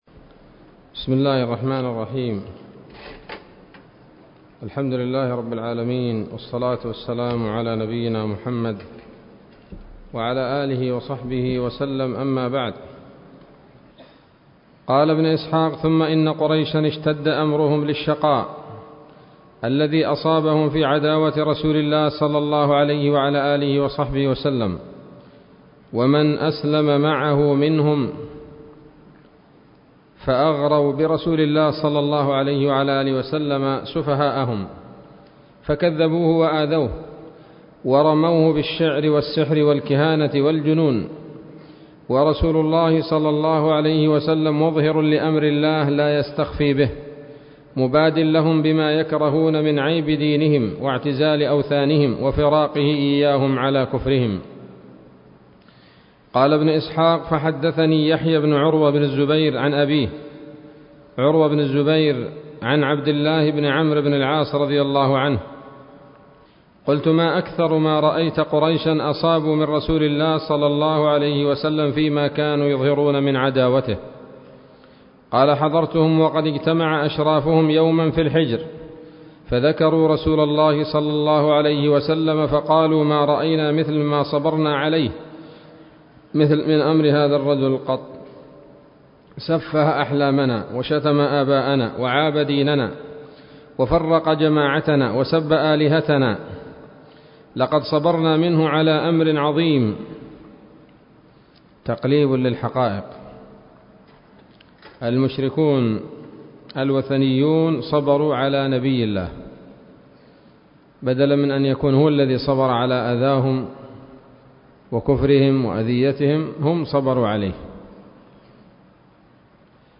الدرس الثلاثون من التعليق على كتاب السيرة النبوية لابن هشام